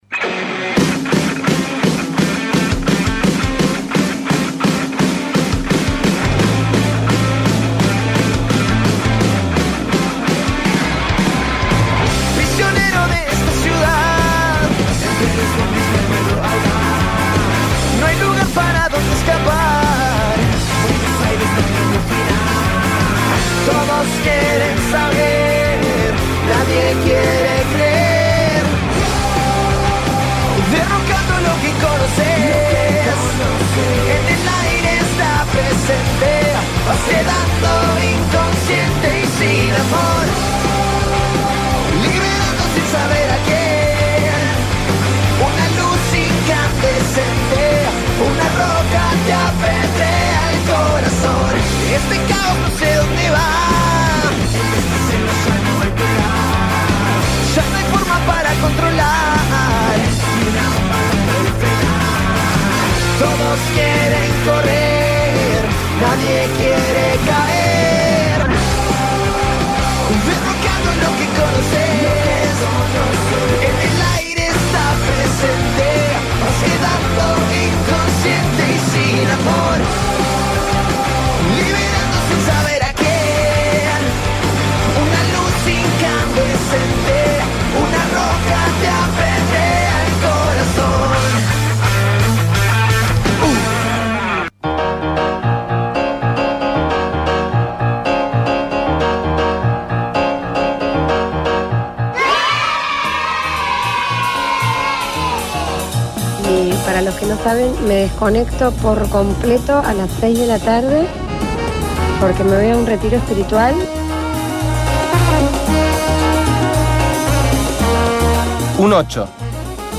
Entrevista telefónica desde Bélgica